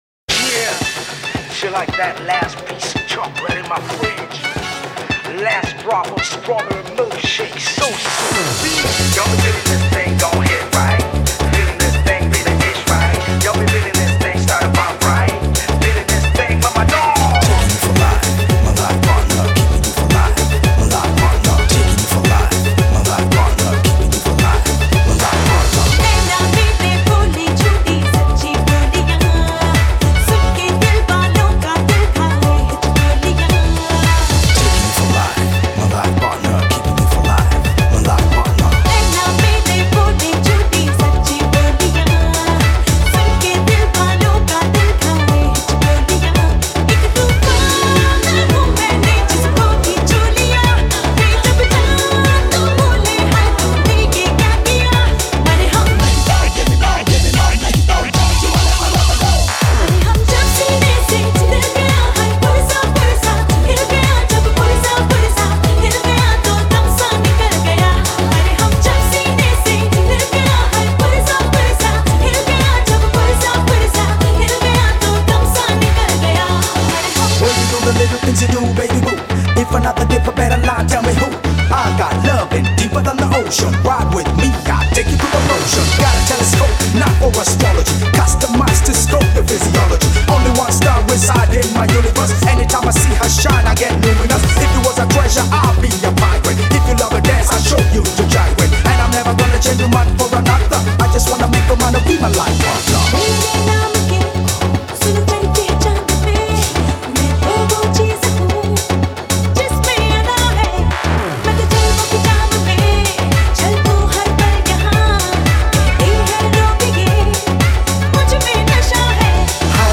2. Bollywood MP3 Songs